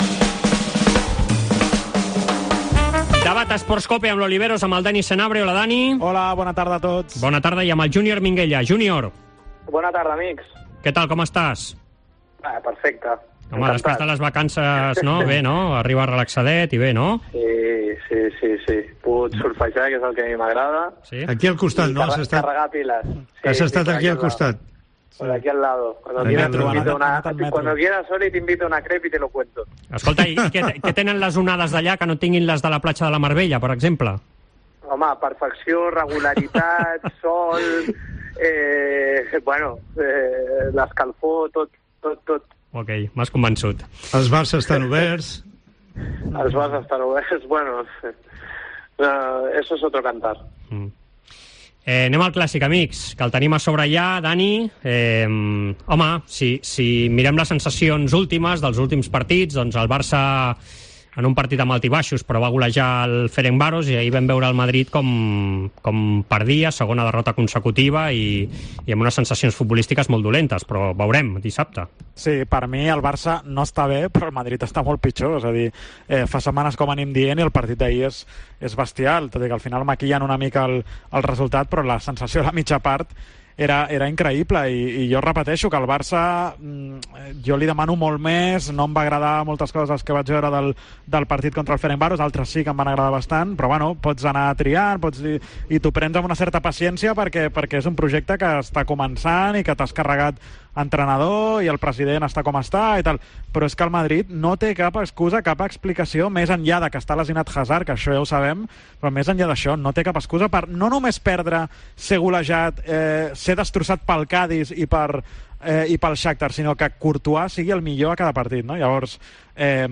Debat